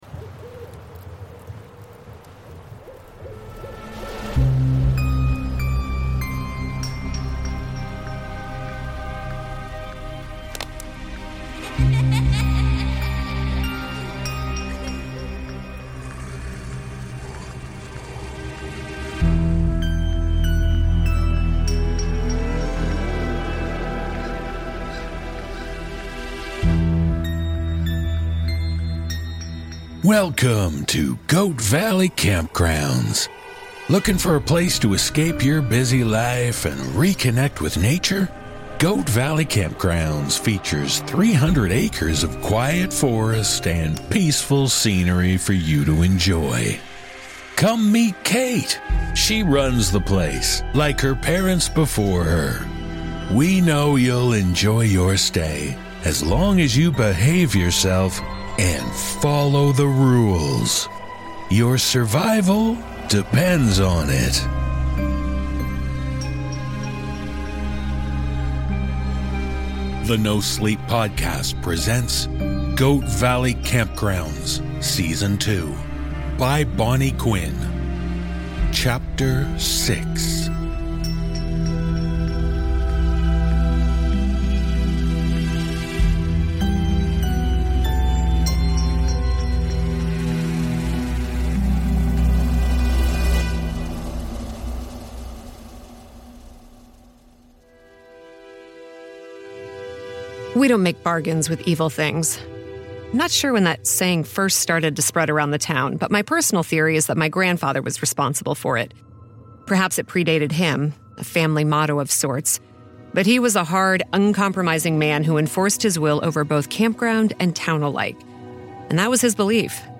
A 12-part horror audio drama